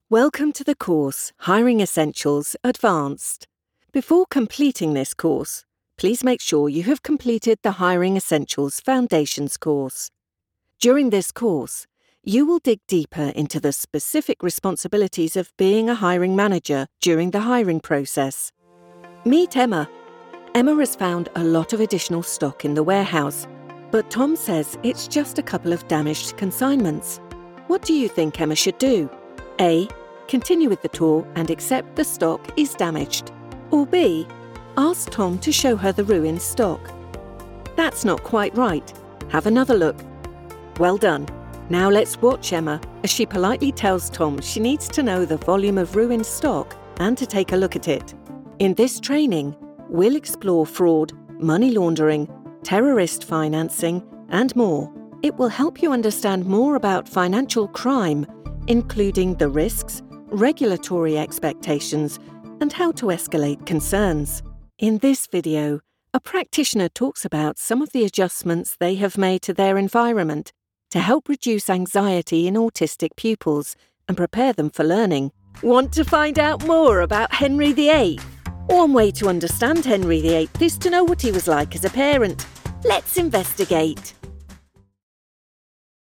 Maduro, Comercial, Versátil, Cálida, Empresarial
E-learning
Su voz es descrita como confiable, juguetona, inteligente y cercana.